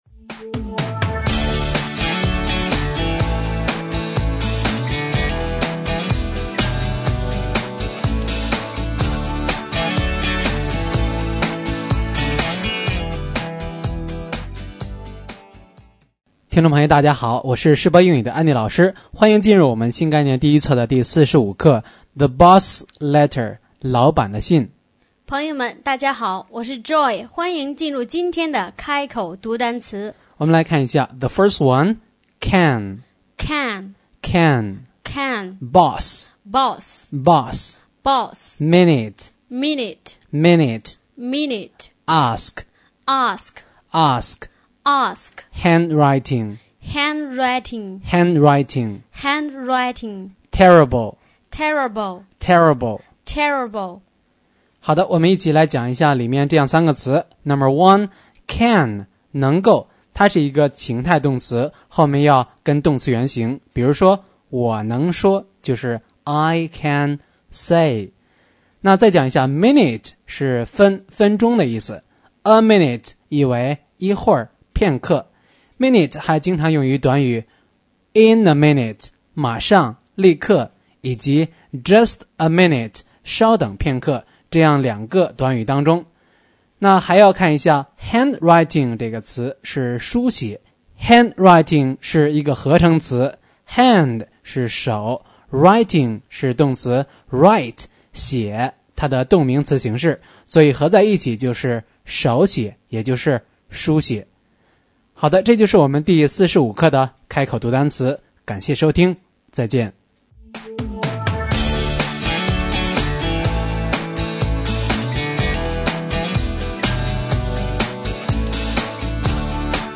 新概念英语第一册第45课【开口读单词】
word045u.mp3